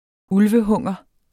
Udtale [ ˈulvəˌhɔŋˀʌ ]